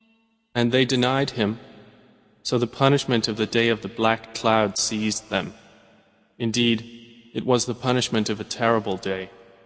متن، ترجمه و قرائت قرآن کریم